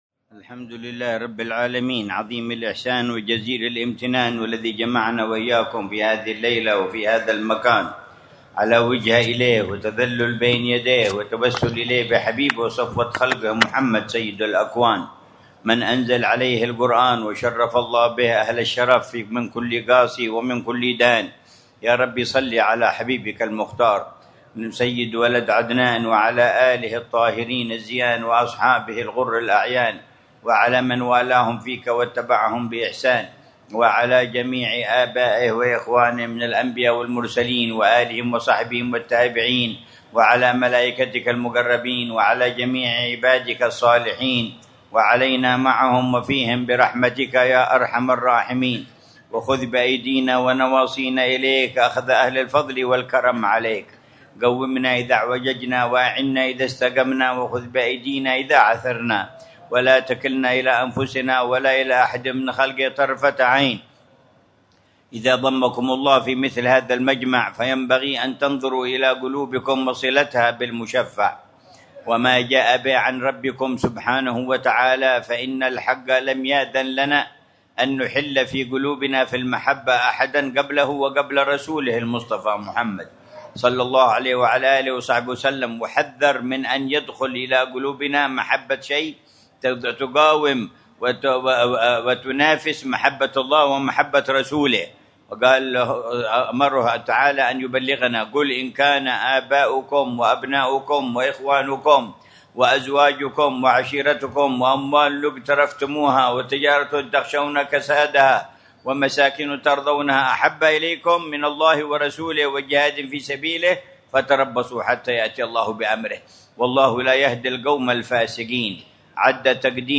مذاكرة العلامة الحبيب عمر بن محمد بن حفيظ في مسجد الوعل، بحارة الخليف مدينة تريم، ليلة السبت 21 ربيع الأول 1447هـ بعنوان